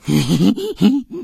PigGrin 01.wav